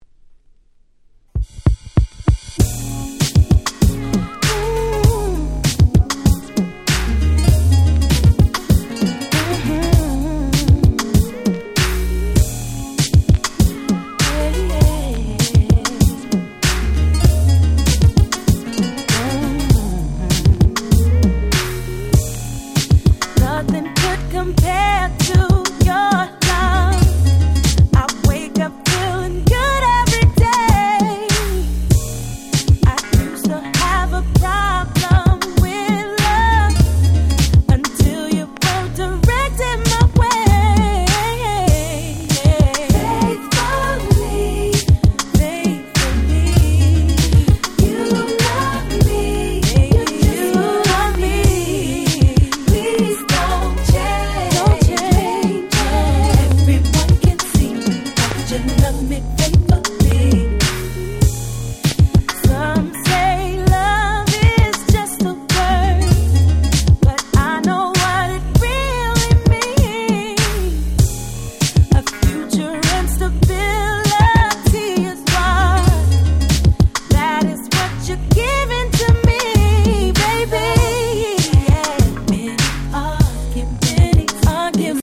01' Smash Hit R&B !!